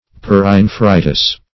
Search Result for " perinephritis" : The Collaborative International Dictionary of English v.0.48: Perinephritis \Per`i*ne*phri"tis\, n. [NL.
perinephritis.mp3